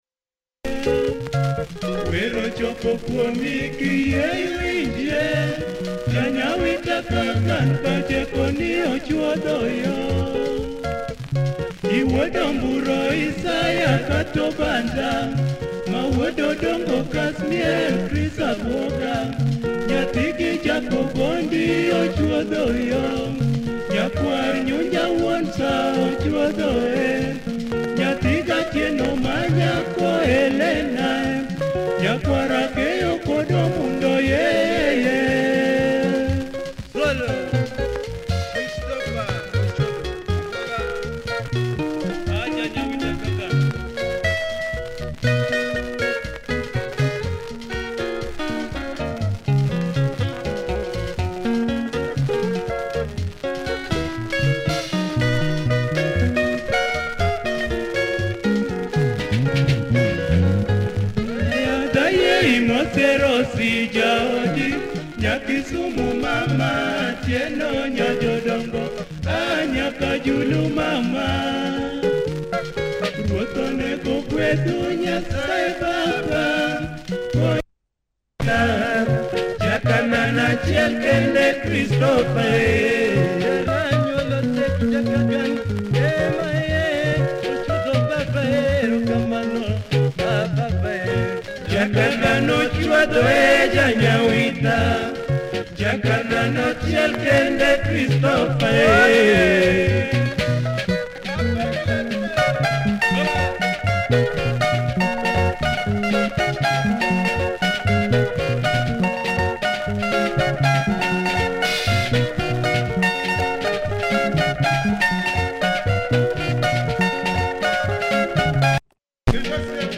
Tanzanian orchestra